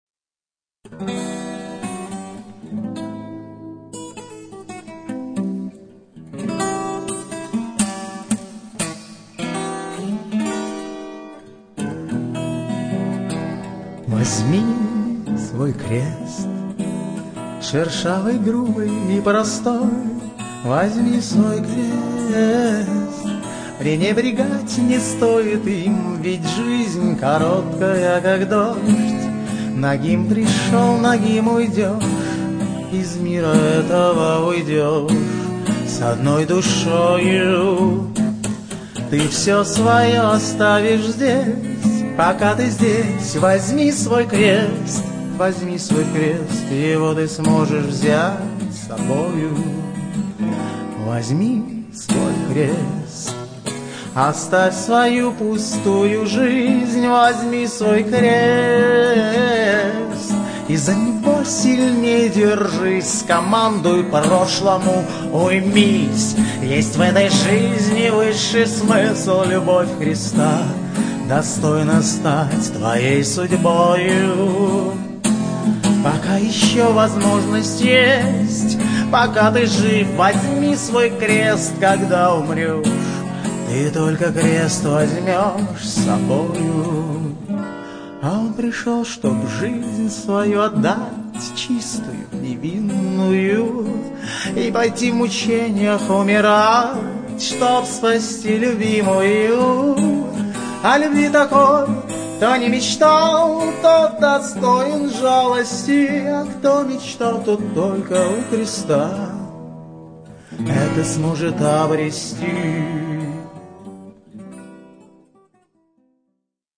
Прекрасный выразительный певец
с крутой профессиональной аранжировкой